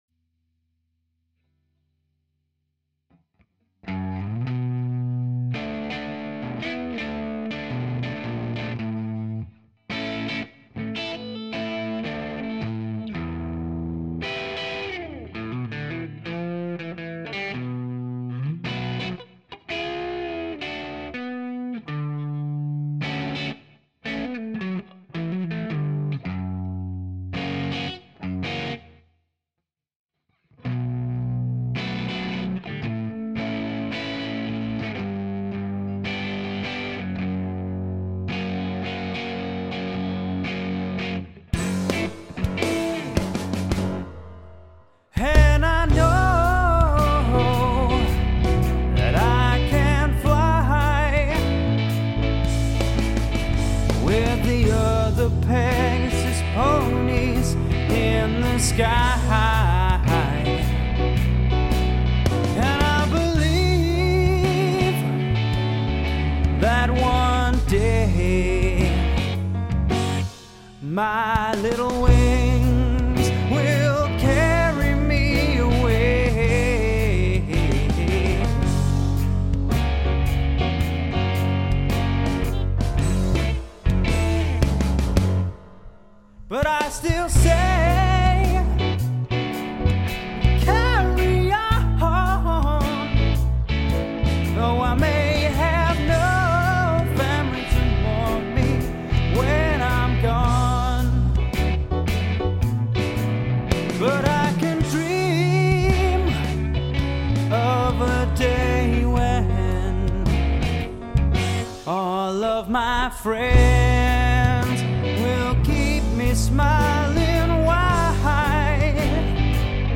Piano
the fantastic piano part